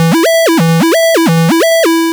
retro_synth_beeps_06.wav